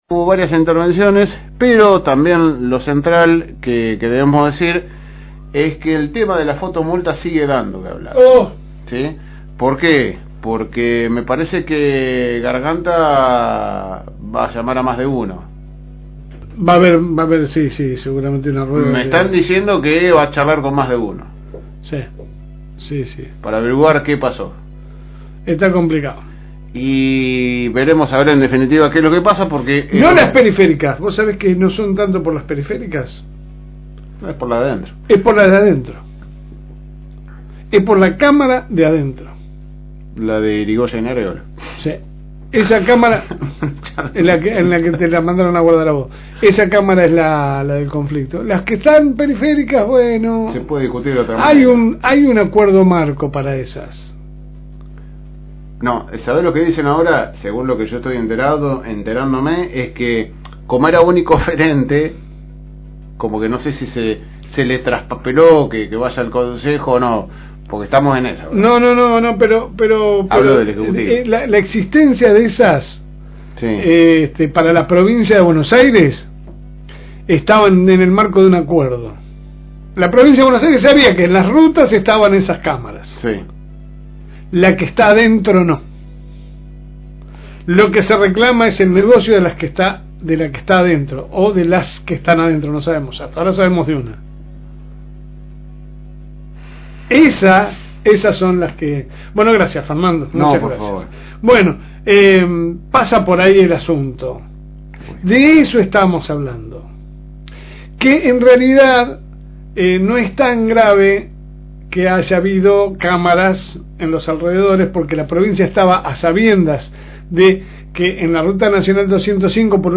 Compartimos con ustedes la editorial